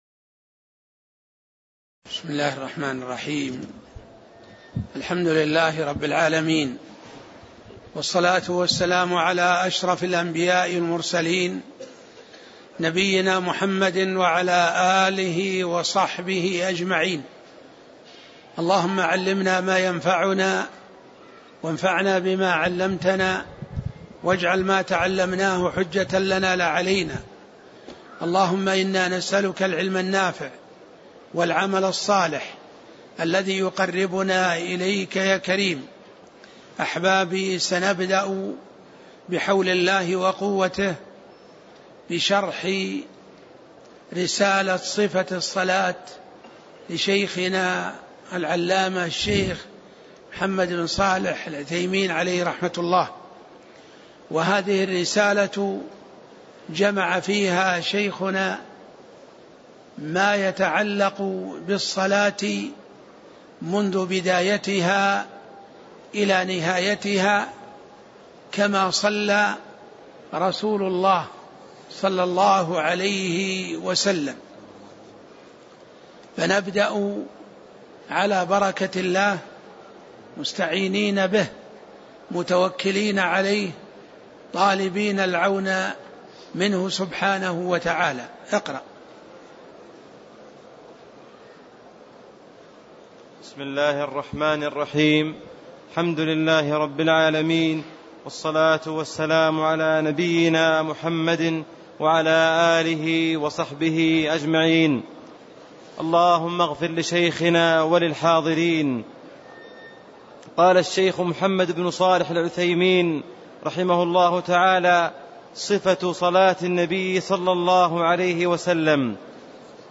تاريخ النشر ١٢ شوال ١٤٣٦ هـ المكان: المسجد النبوي الشيخ